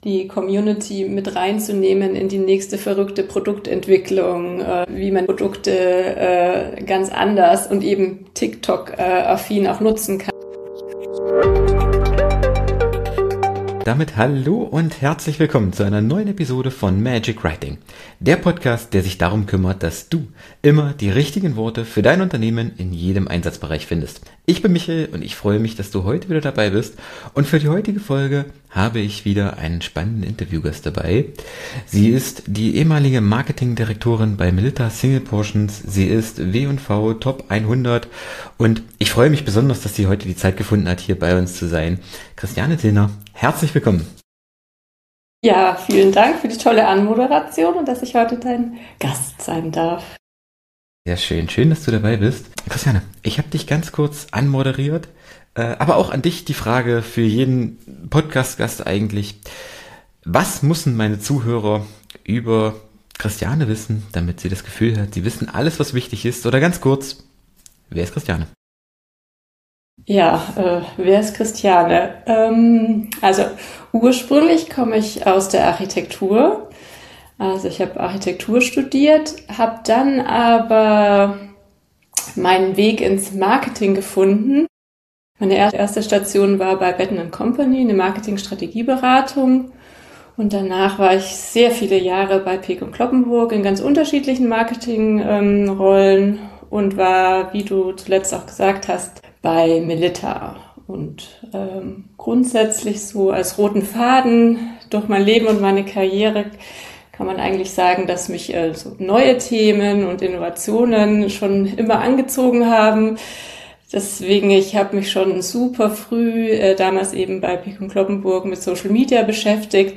Folge 314: Hidden Champions und ihre versteckten Geschichten – Interview